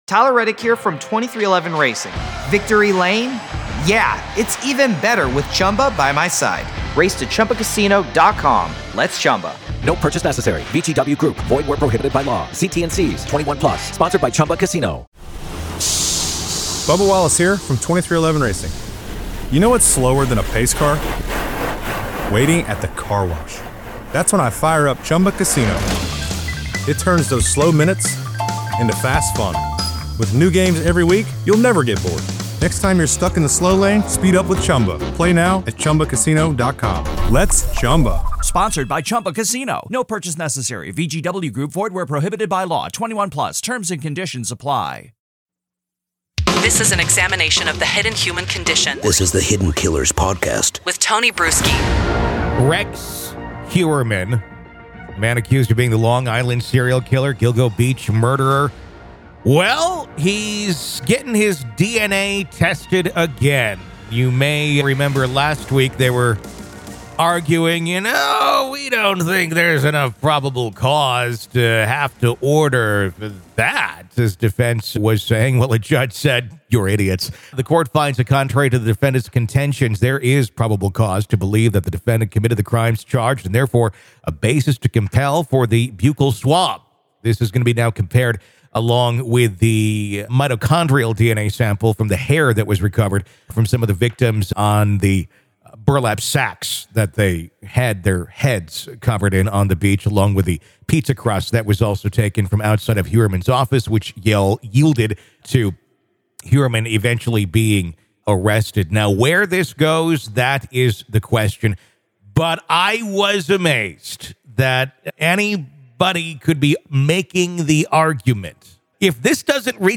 riveting conversation